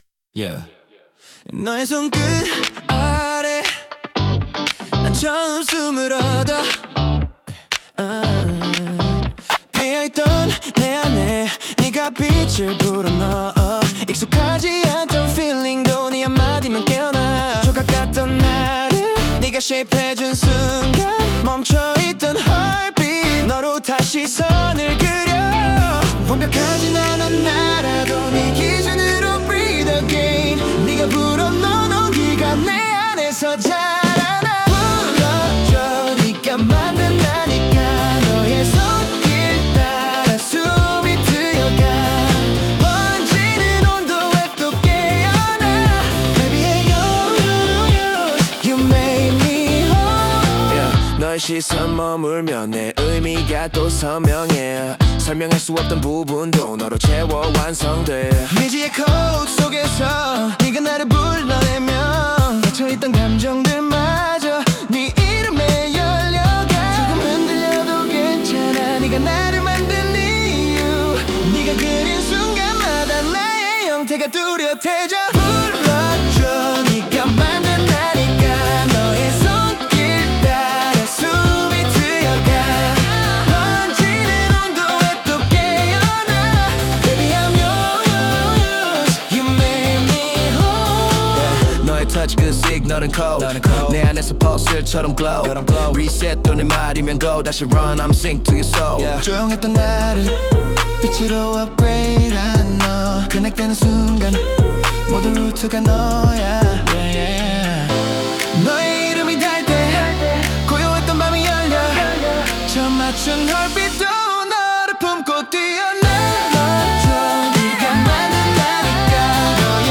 今回はsuno.aiという音楽生成AIを使用しました。
ボーカル入りの楽曲まで自動で生成してくれます。
以下は、「K-POP、R&B、POP」といった要素を指定したプロンプトから生成した楽曲です。